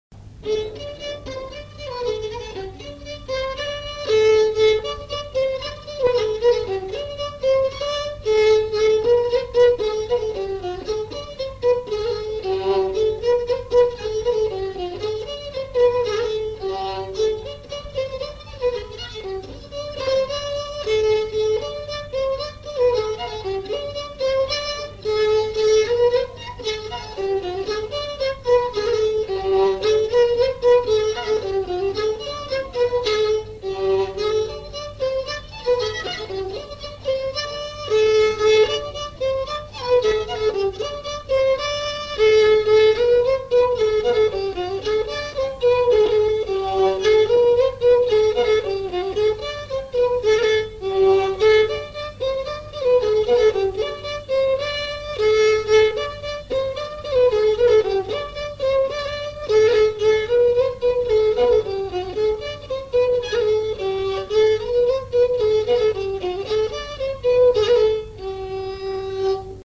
danse : branle : courante, maraîchine
enregistrements du Répertoire du violoneux
Pièce musicale inédite